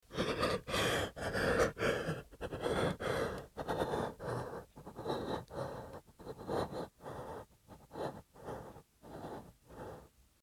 男性
青年ボイス～ホラー系ボイス～
【声を殺す】